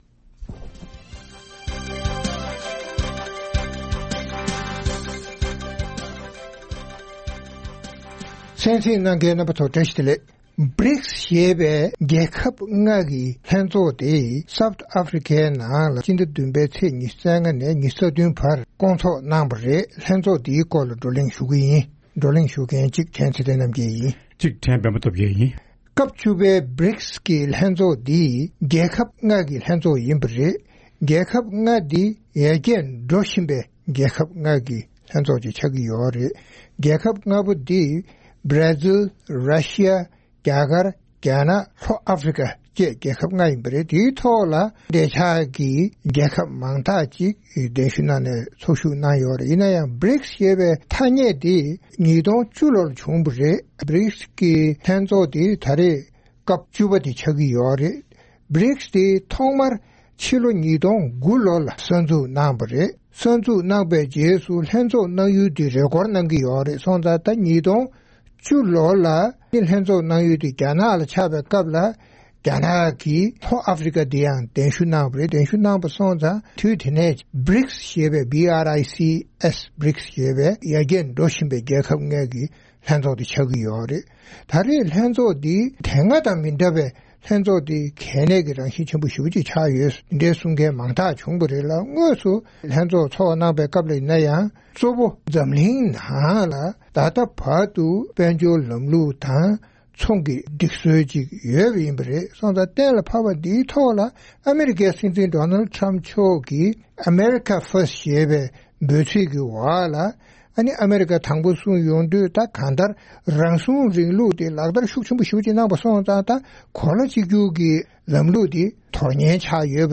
རྩོམ་སྒྲིག་པའི་གླེང་སྟེགས་ཞེས་པའི་ལེ་ཚན་ནང་། རྒྱ་ནག་གི་སྲིད་འཛིན་དང་རྒྱ་གར་གྱི་སྲིད་བློན་སོགས་ BRICS ཞེས་པའི་ཡར་རྒྱས་འགྲོ་བཞིན་པའི་རྒྱལ་ཁབ་ལྔའི་སྐབས་བཅུ་པའི་ལྷན་ཚོགས་གནང་བའི་སྐོར་རྩོམ་སྒྲིག་འགན་འཛིན་རྣམ་པས་བགྲོ་གླེང་གནང་བ་ཞིག་གསན་རོགས་གནང་།